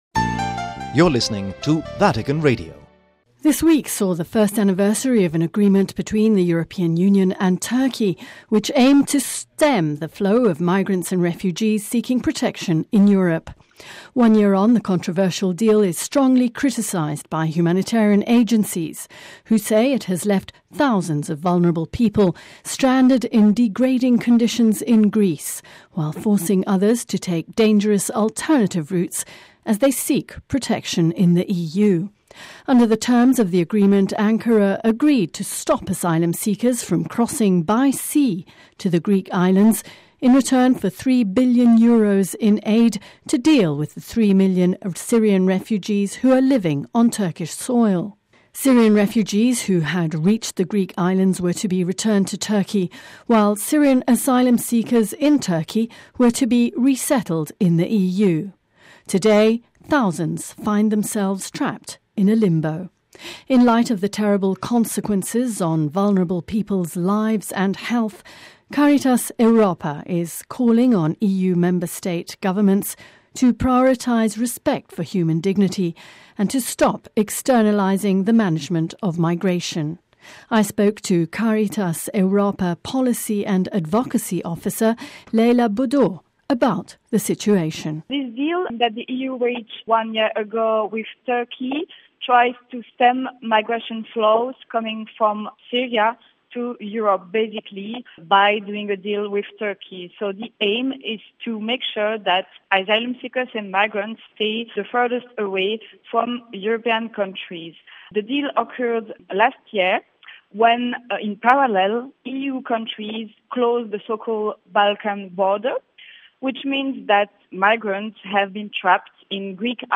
(Vatican Radio) This week sees the first anniversary of an agreement between the European Union and Turkey which aimed to stem the flow of migrants and refugees seeking protection in Europe.